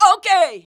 O.K.wav